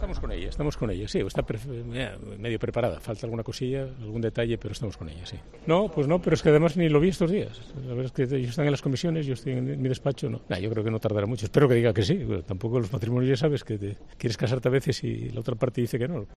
Además, no tiene previsto hacer otro ofrecimiento similar a ninguna persona de otro partido, según ha declarado a preguntas de los periodistas en un acto en Oviedo.